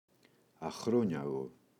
αχρόνιαγο, το [a’xroɲaγo] – ΔΠΗ